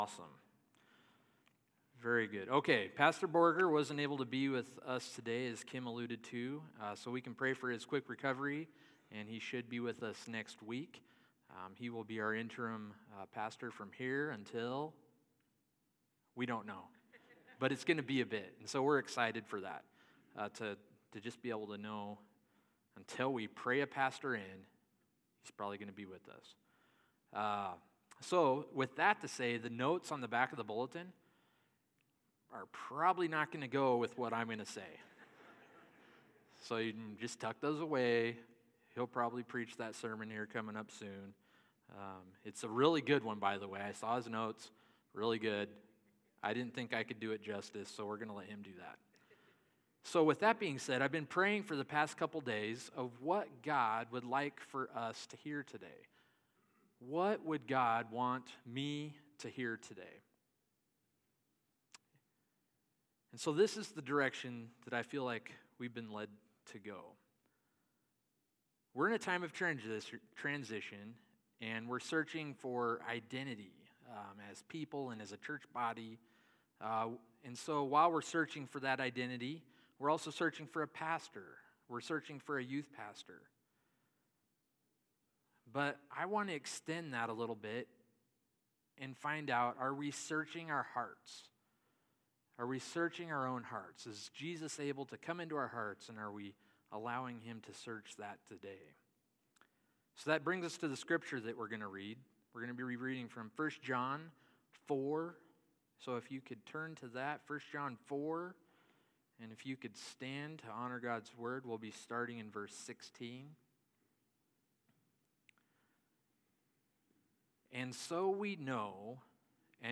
9.11.22-Sermon.mp3